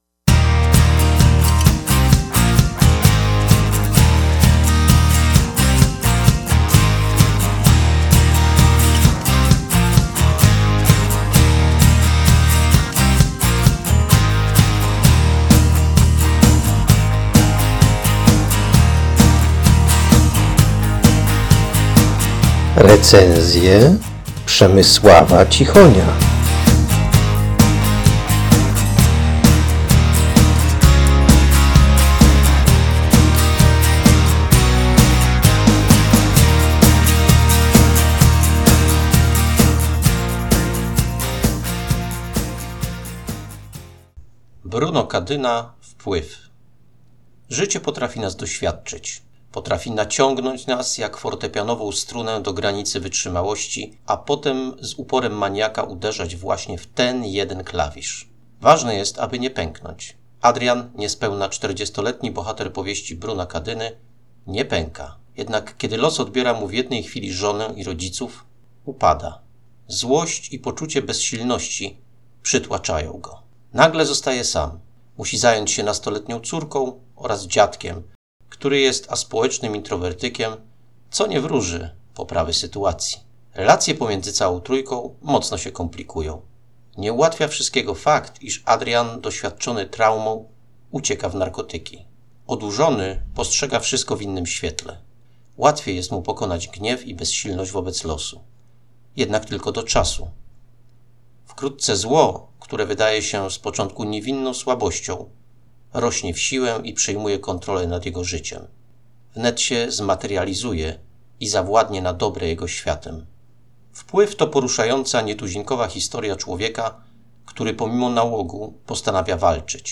Czyta